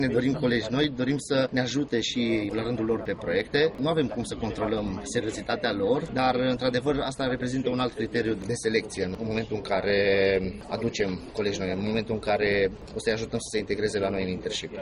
Reprezentanții companiilor caută specialiști, care sunt tot mai rari pe o piață a muncii în care ofertele din străinătate sunt pe primul loc în preferințele tinerilor: